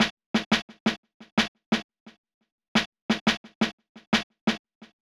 SNARE L_R.wav